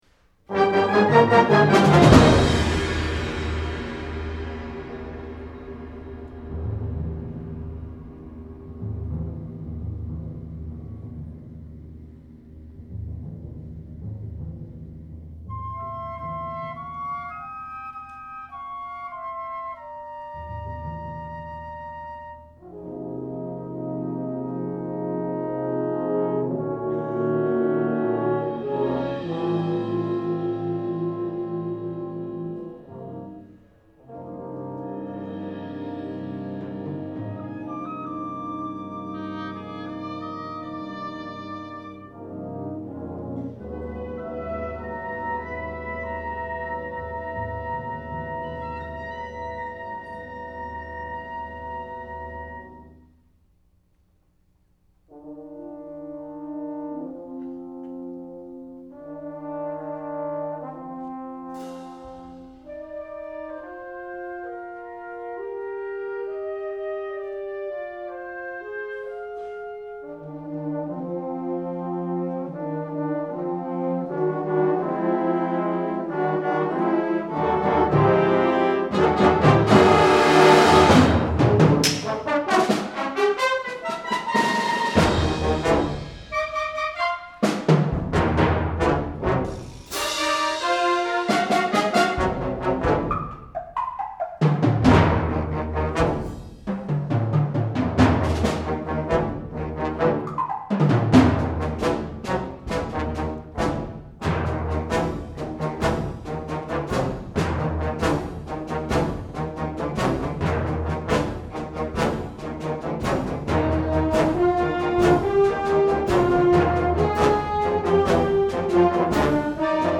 Genre: Band